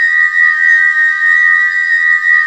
A#4 WHIST03R.wav